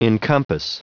Prononciation du mot encompass en anglais (fichier audio)
Prononciation du mot : encompass